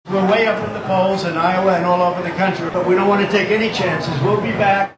Trump had several of the Floridians who’ve endorsed him speak to the crowd at a bar on the fairgrounds, then Trump left the crowd with this message: